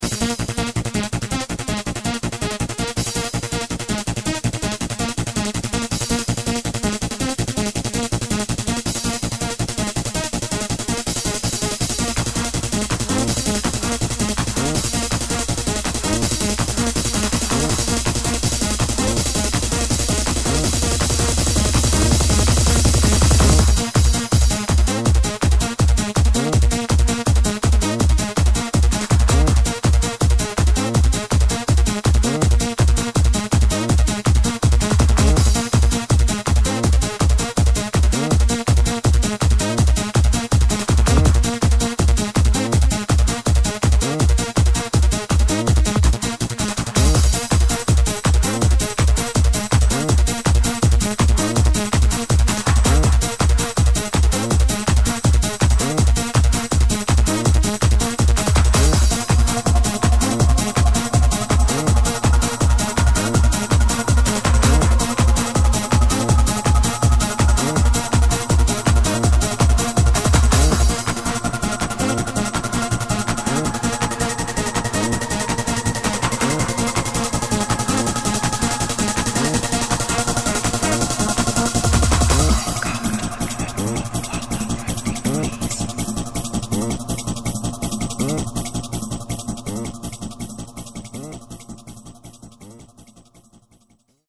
Hard-Trance